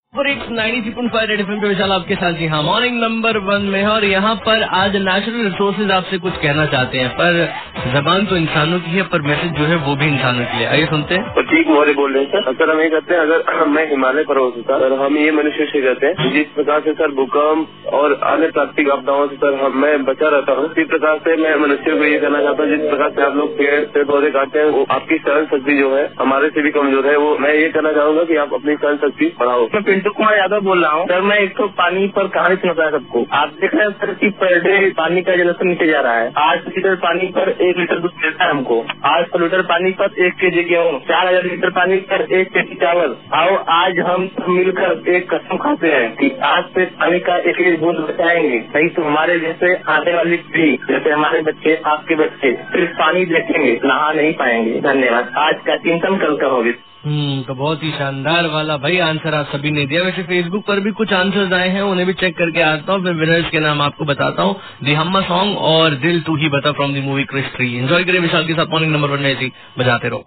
CALLERS ABOUT NATURAL RESOURCES